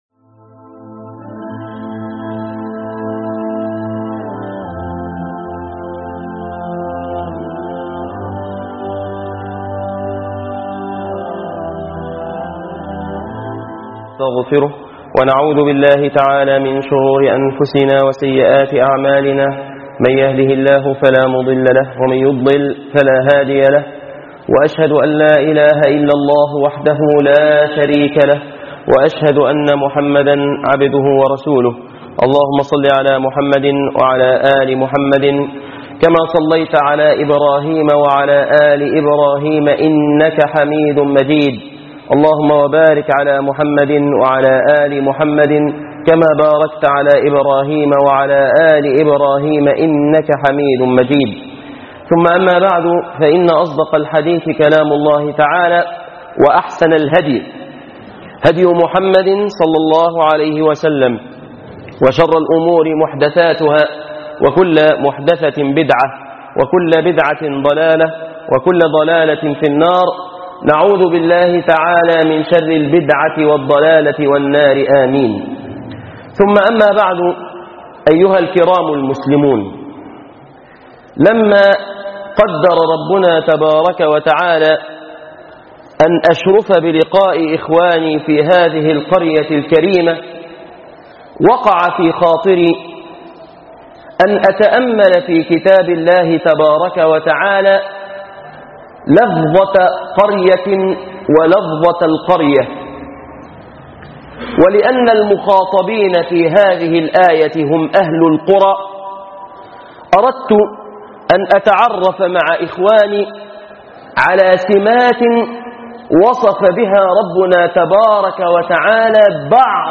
أهلكناهم لما ظلموا ( رسائل لأهل القرى ) * خطبة الجمعة *